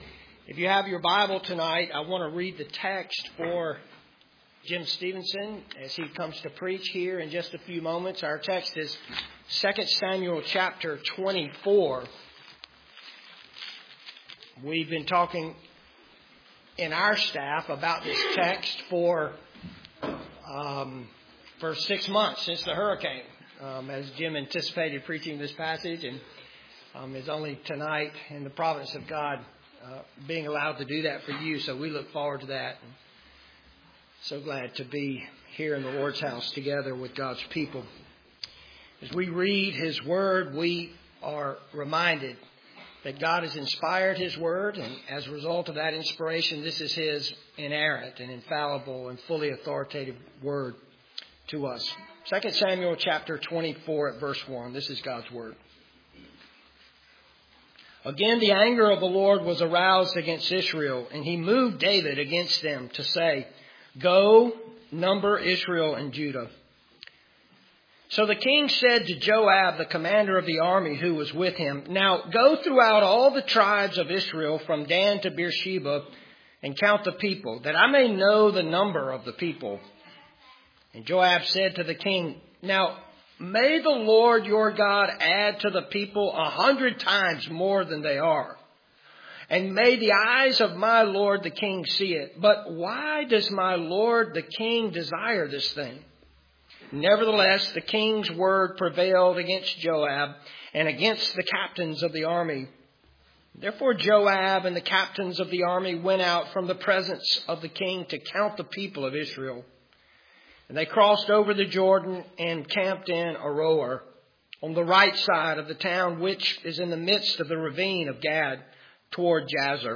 2 Samuel 24:1-25 Service Type: Sunday Evening 2 Samuel 24:1-25 When the leaders sin